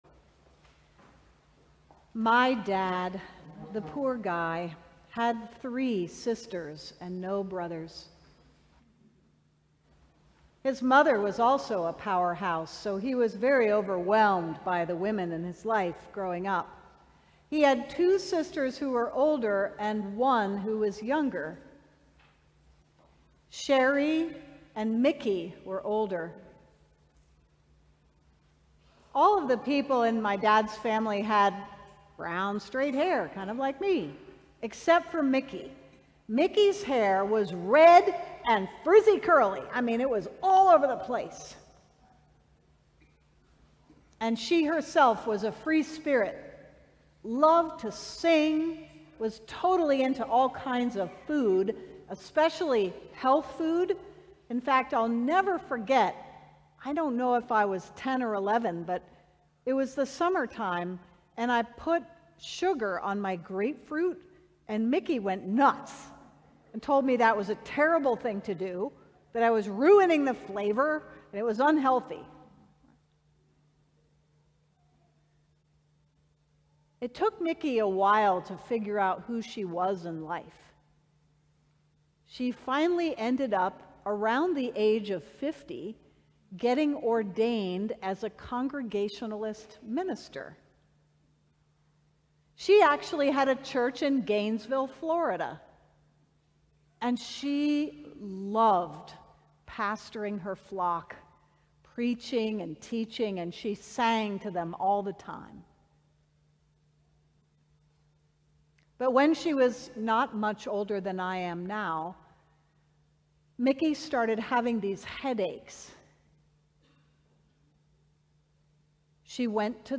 The Seventh Sunday after Pentecost We invite you to join us in worship at St. […]
Sermons from St. John's Cathedral